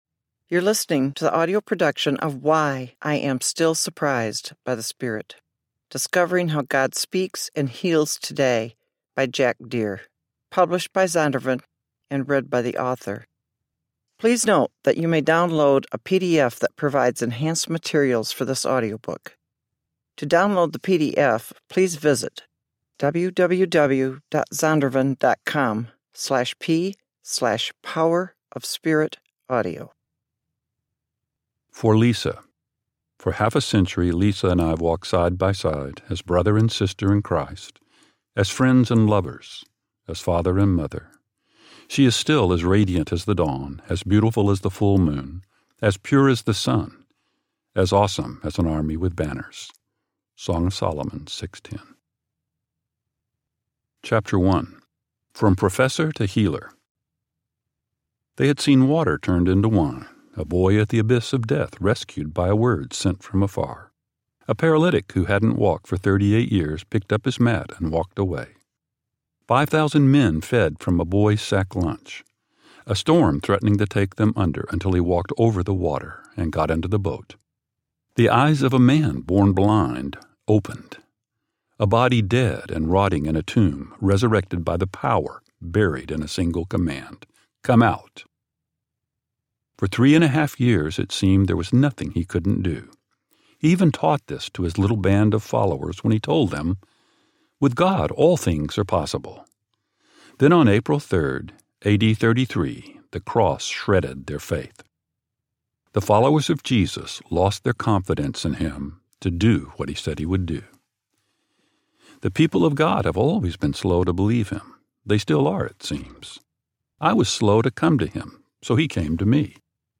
Why I Am Still Surprised by the Power of the Spirit Audiobook
Narrator